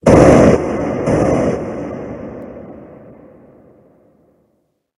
Cri de Ronflex Gigamax dans Pokémon HOME.
Cri_0143_Gigamax_HOME.ogg